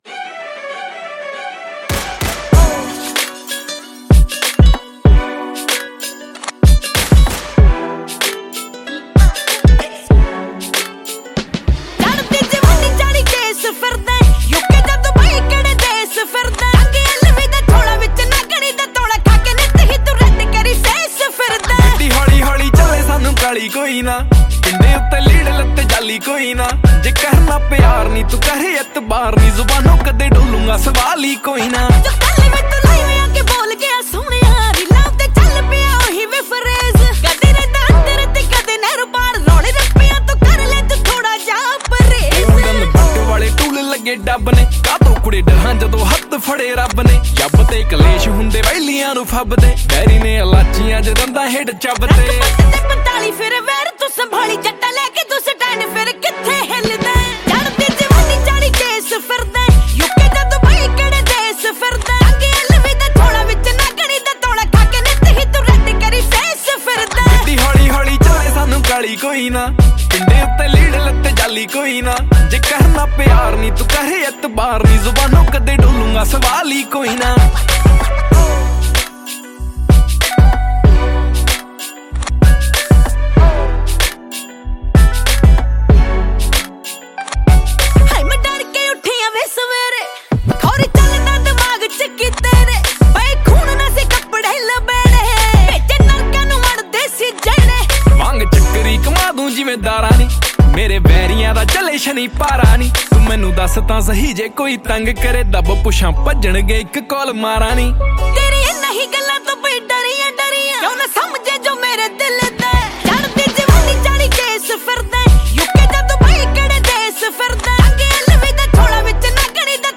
2. Punjabi Single Track